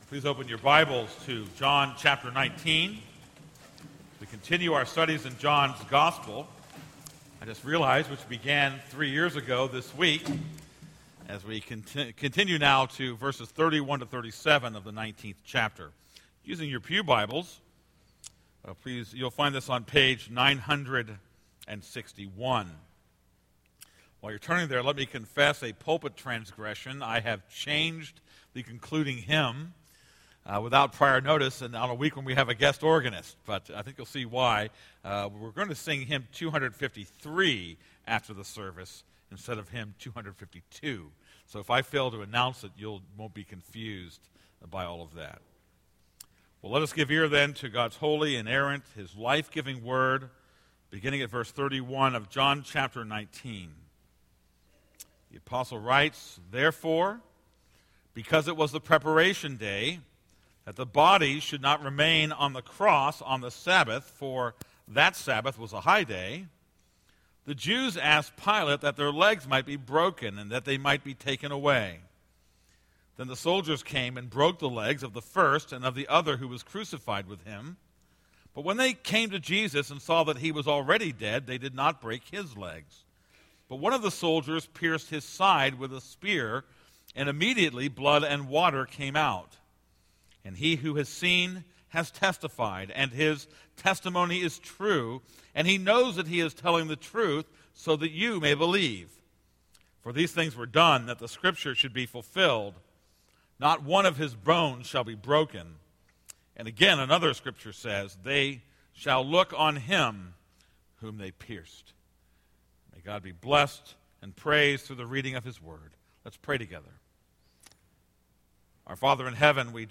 This is a sermon on John 19:31-37.